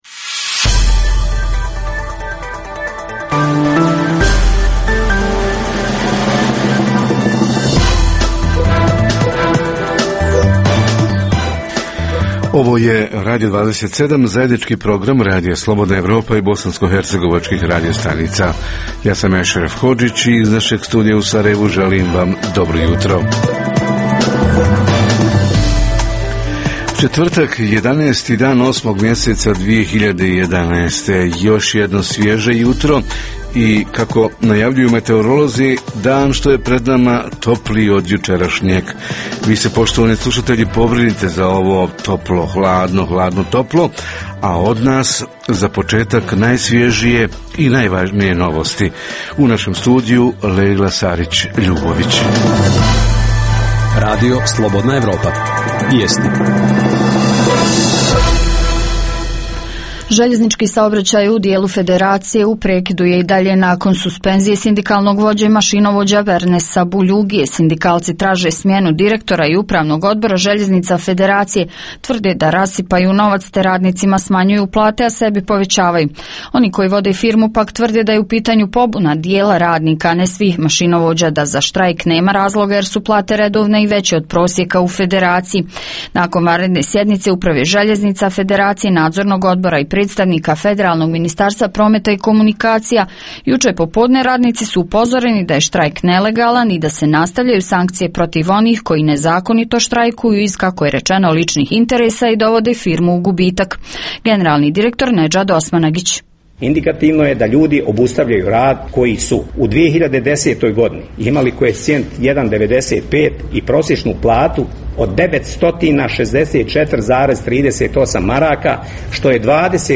Na putevima u BiH skoro 80 odsto neispravnih automobila – zašto vlasnici vozila ne otklanjaju te neispravnosti, kako “prolaze tehnički pregled” i kako do “ispravnijih” automobila? Reporteri iz cijele BiH javljaju o najaktuelnijim događajima u njihovim sredinama.
Redovni sadržaji jutarnjeg programa za BiH su i vijesti i muzika.